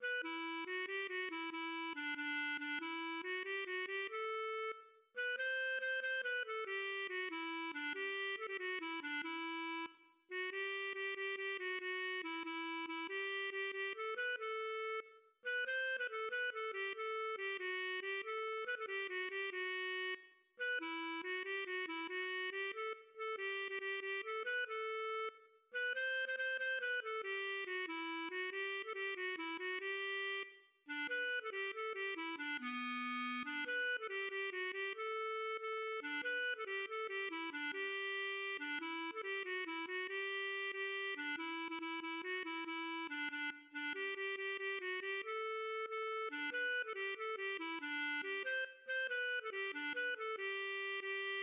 \set Staff.midiInstrument="clarinet"